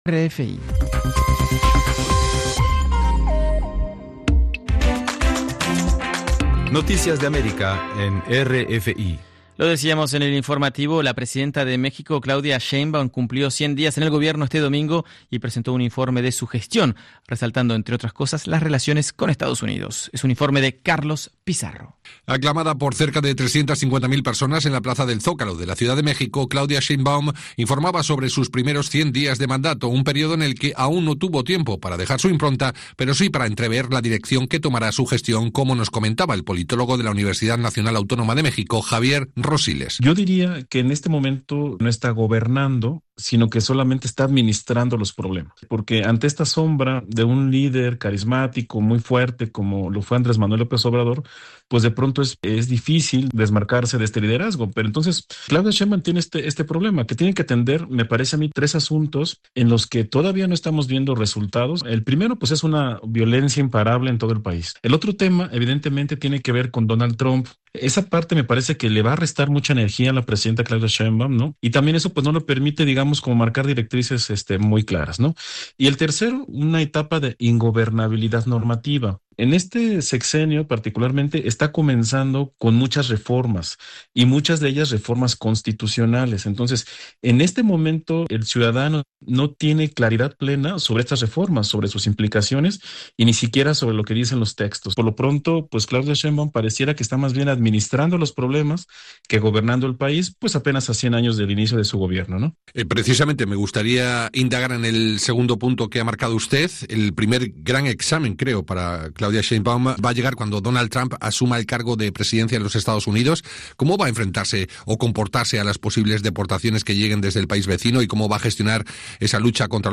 Noticieros